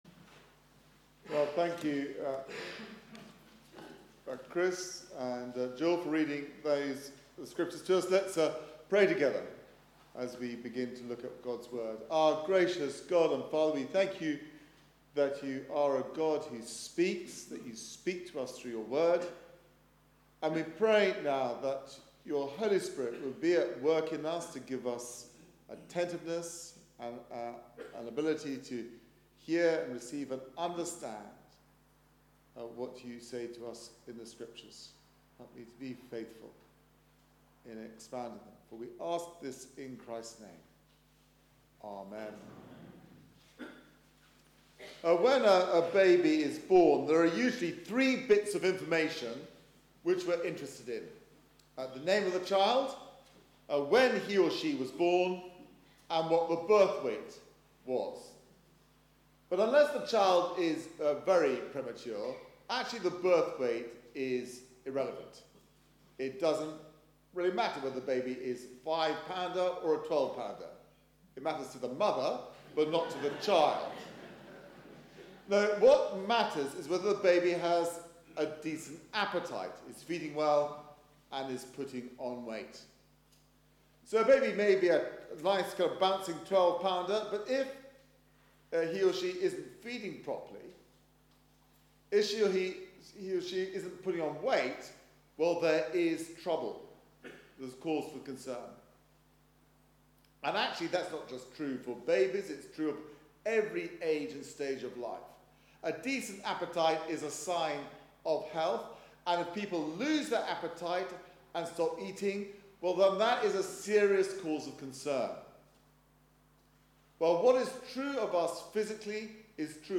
Media for 11am Service on Sun 30th Oct 2016 11:00
Series: The Beatitudes Theme: Blessed are those who hunger and thirst for righteousness Sermon